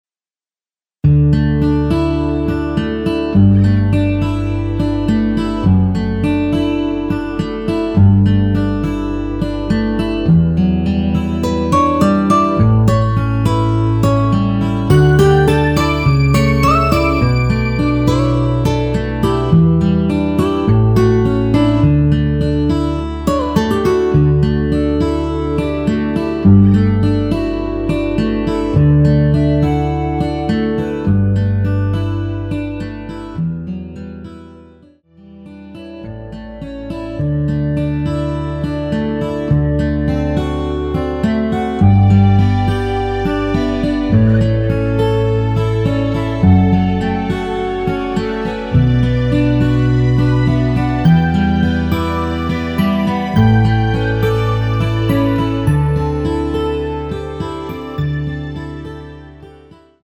원키 멜로디 포함된 MR 입니다.(미리듣기 참조)
D
앞부분30초, 뒷부분30초씩 편집해서 올려 드리고 있습니다.
중간에 음이 끈어지고 다시 나오는 이유는